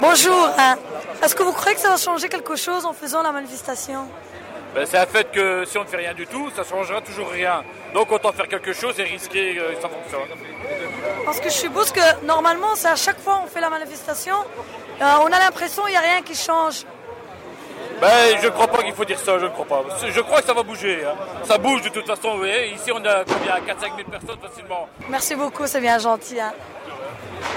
Interview de manifestants